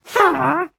Minecraft Version Minecraft Version snapshot Latest Release | Latest Snapshot snapshot / assets / minecraft / sounds / entity / witch / ambient2.ogg Compare With Compare With Latest Release | Latest Snapshot